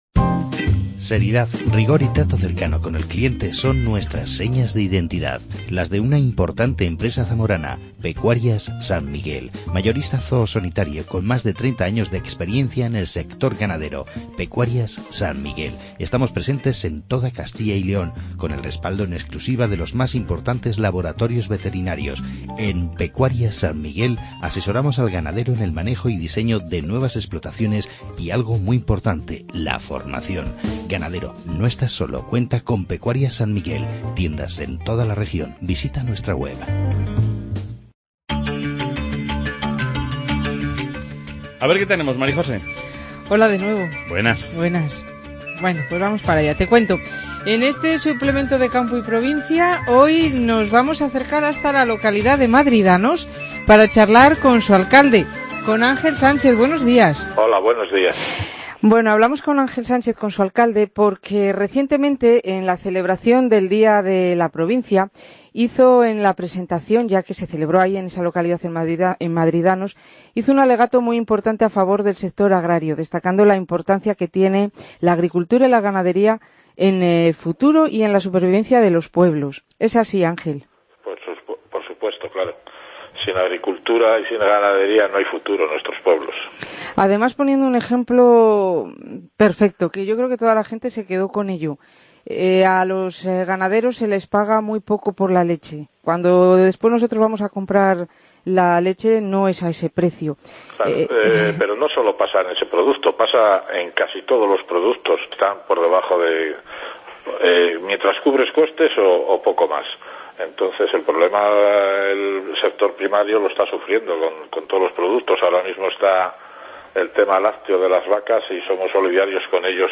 AUDIO: Charla con el alcalde de Madridanos, Ángel Sánchez, sobre el sector agropecuario.